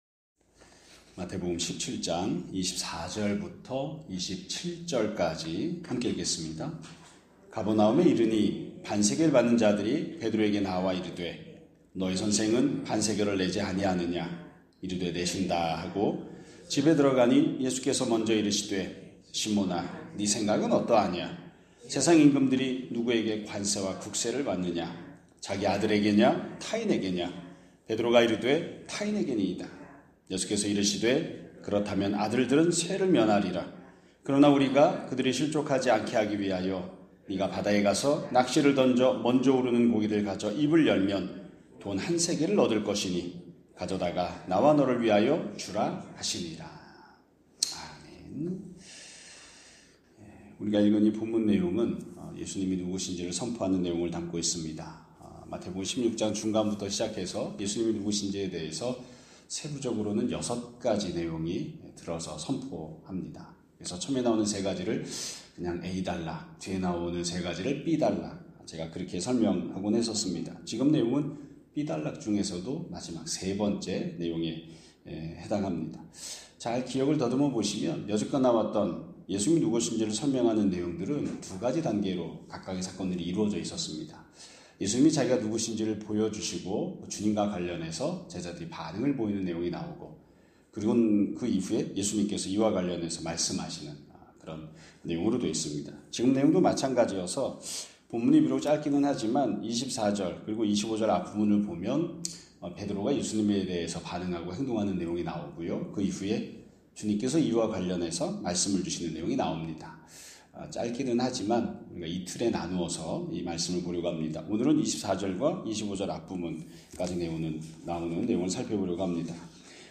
2025년 12월 1일 (월요일) <아침예배> 설교입니다.